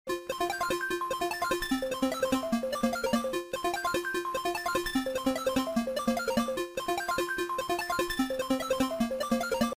ringtone